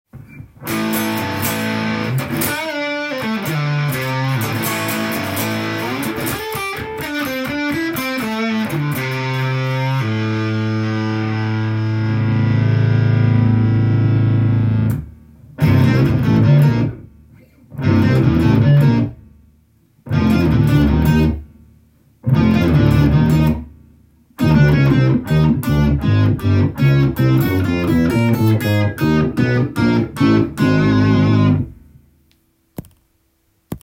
歪み系エフェクターのファズとしても使える代物です。
試しに弾いてみました
ファズモードで弾いた後に
低音を出してオクターバーとして使いました。